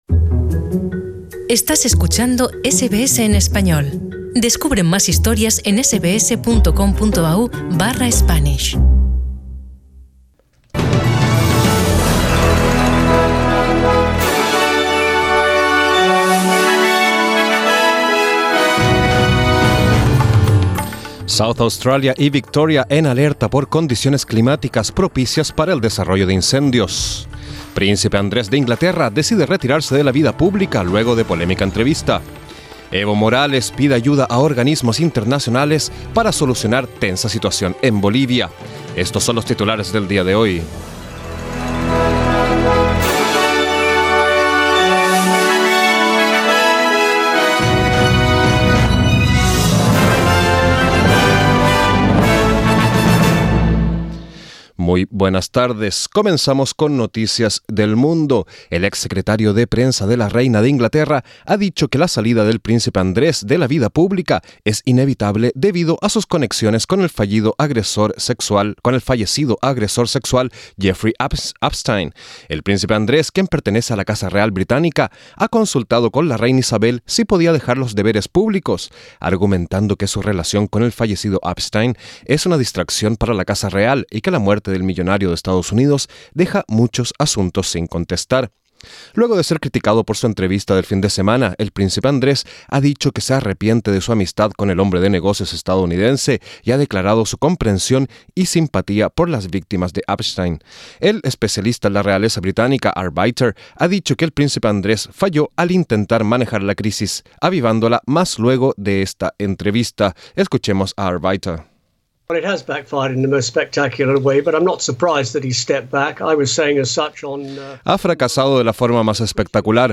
Noticias SBS Spanish | 21 noviembre 2019